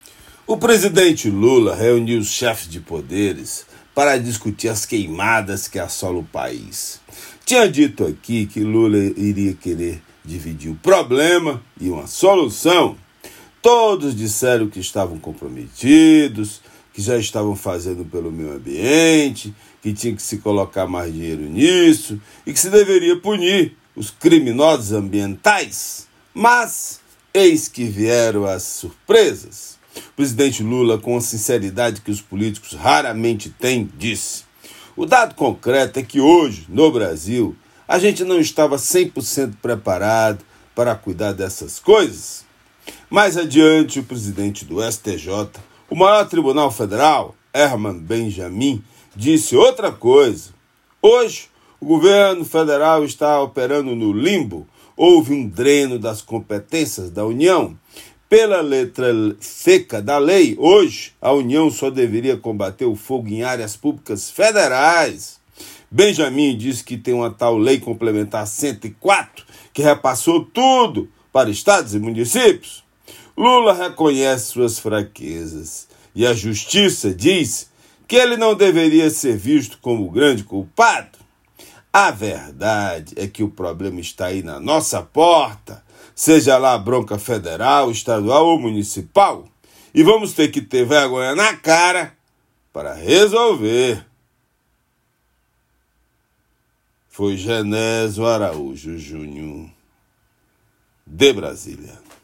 Comentário desta quarta-feira
direto de Brasília.